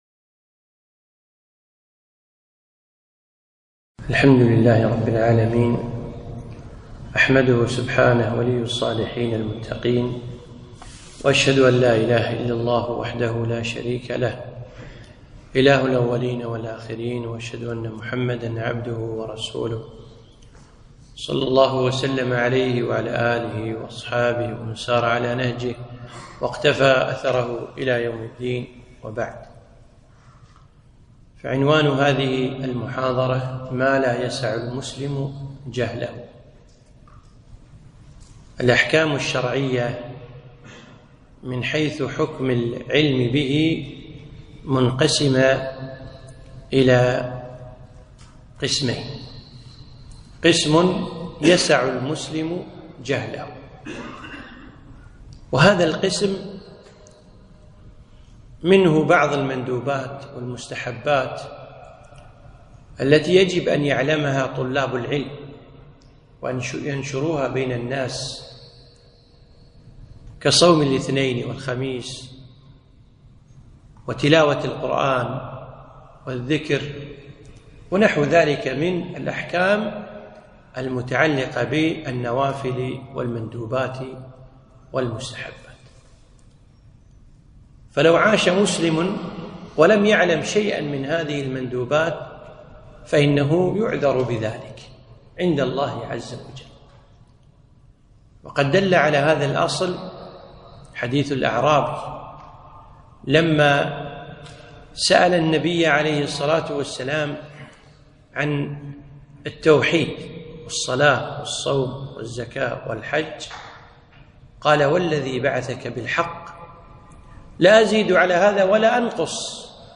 محاضرة - ما لا يسع المسلم جهله